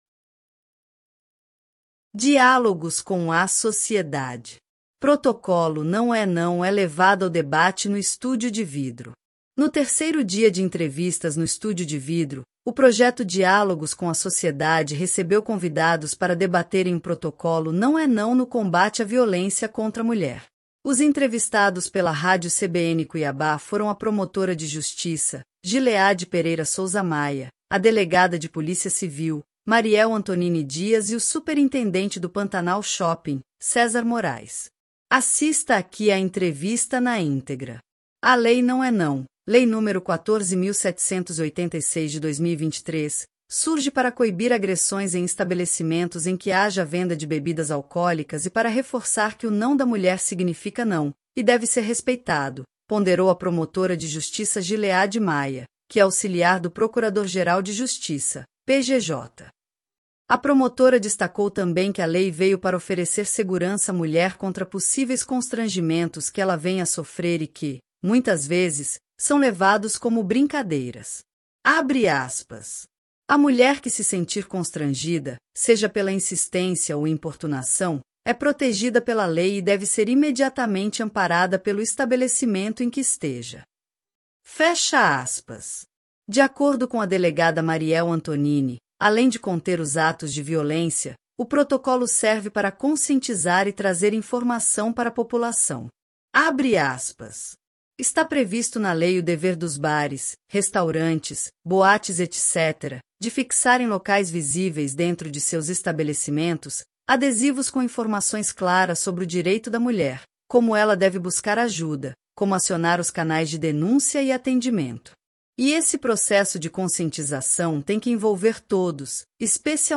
Protocolo “Não é Não” é levado ao debate no estúdio de vidro